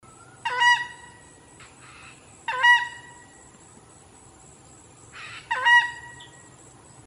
Tachã (Chauna torquata)
Nome em Inglês: Southern Screamer
Fase da vida: Adulto
Detalhada localização: Laguna Curichi Cuajo
Condição: Selvagem
Certeza: Observado, Gravado Vocal